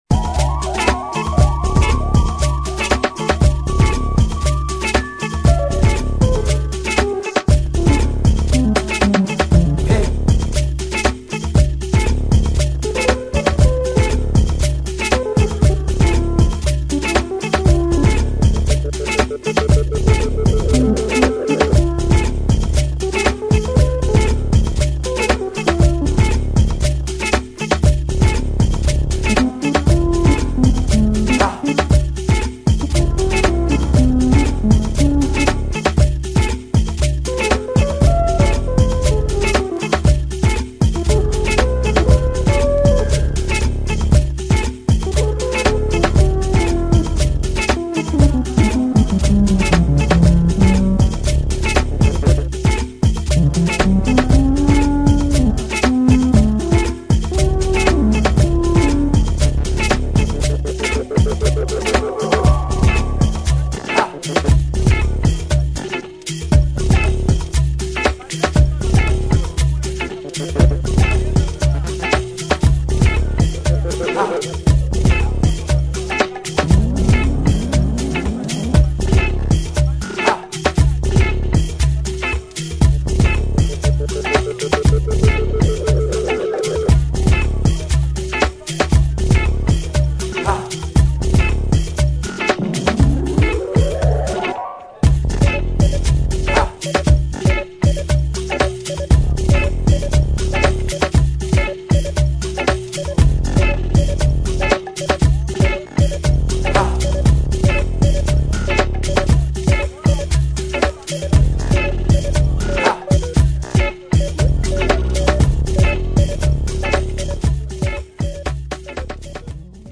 [ AFRO BEAT / WORLD / DEEP HOUSE ]